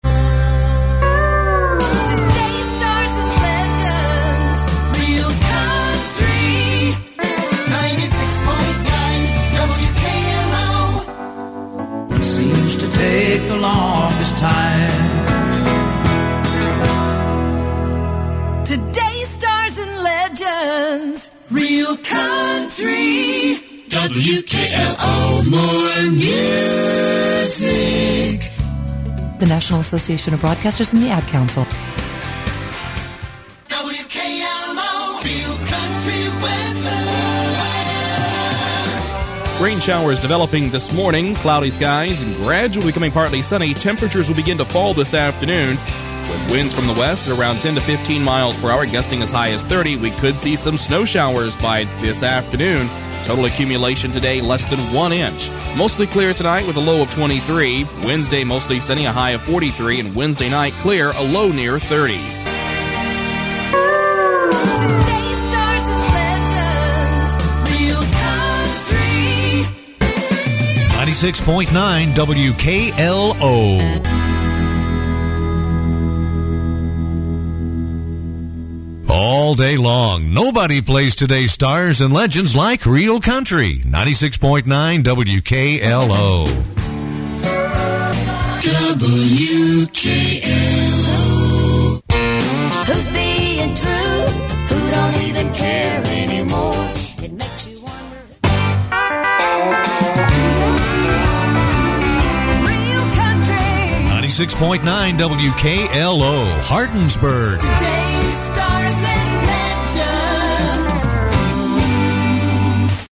WKLOHardinsbirgIN2007Aircheck.mp3